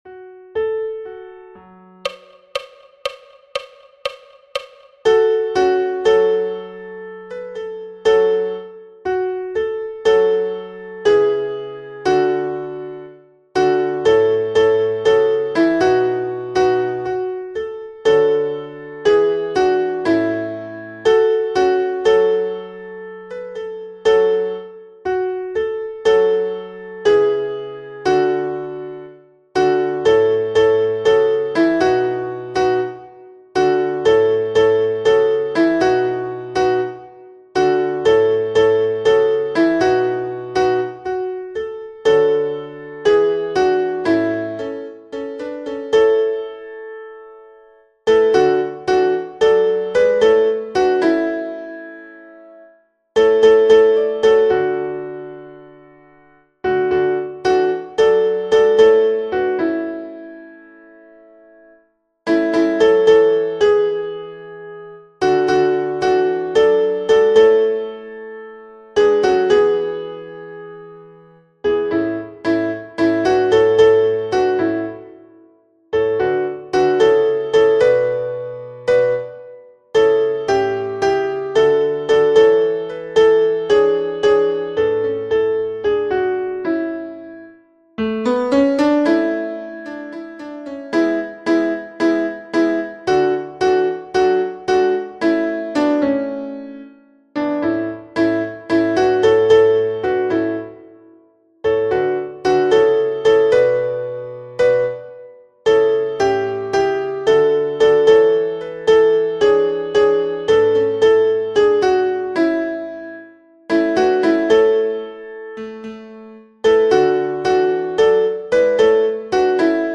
storm-alto.mp3